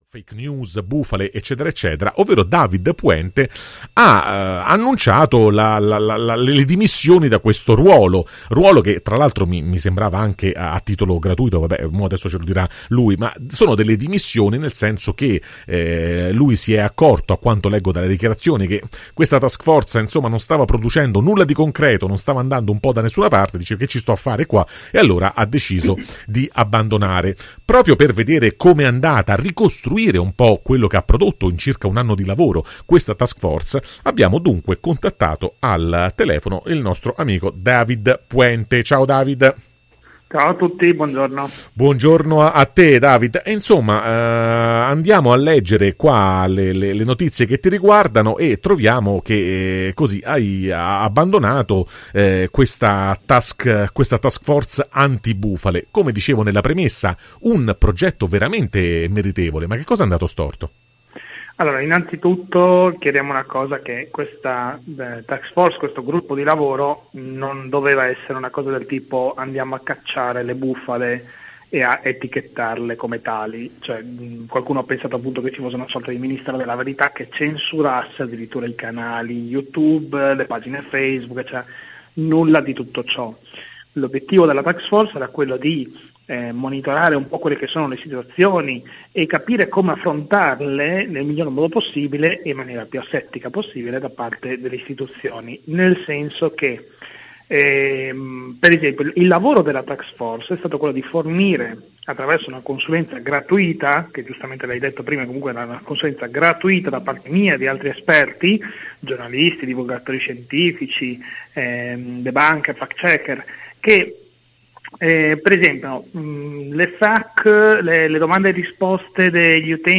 PODCAST INTERVISTA